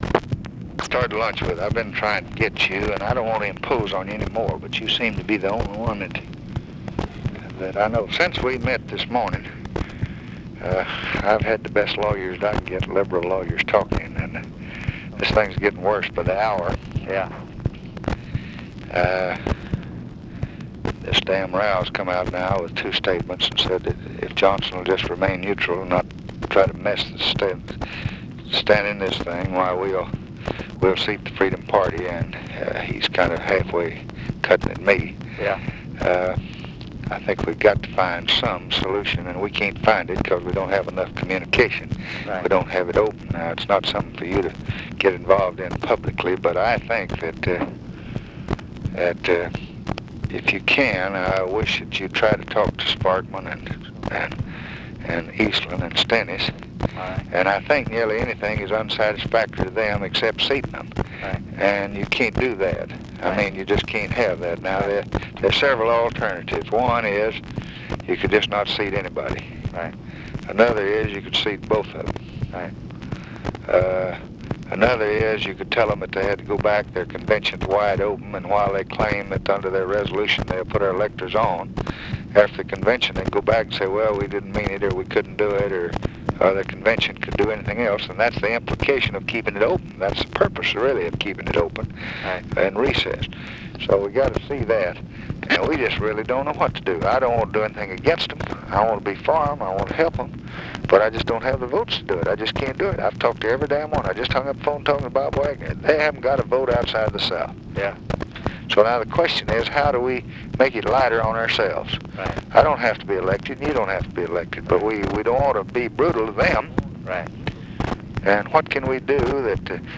Conversation with GEORGE SMATHERS, August 18, 1964
Secret White House Tapes